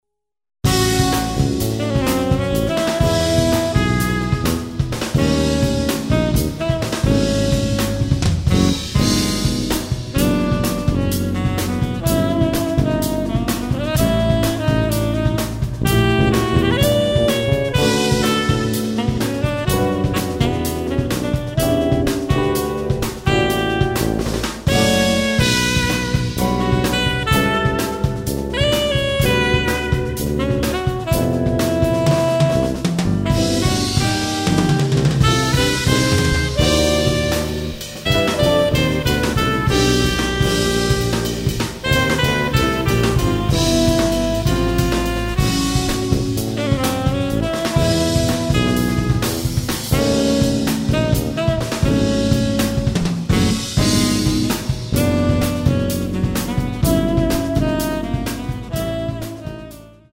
electric guitars & MIDI programming
drums
electric bass
piano
tenor saxophone